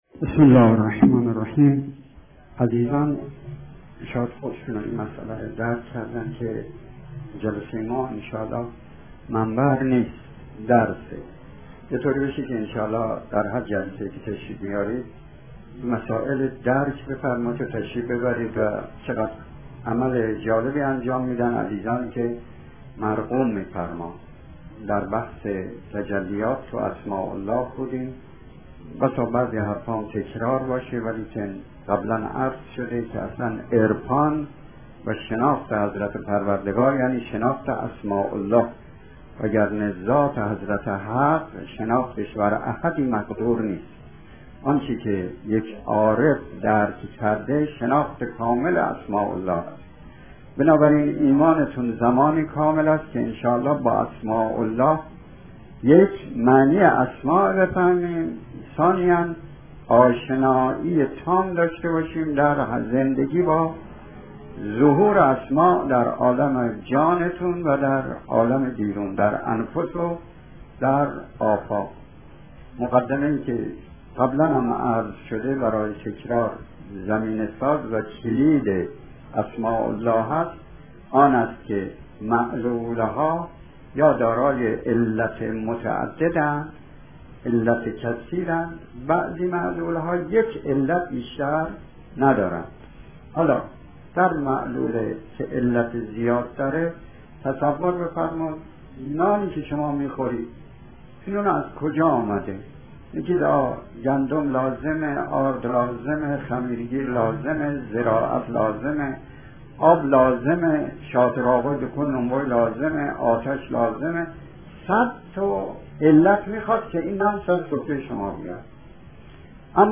جلسات سخنرانی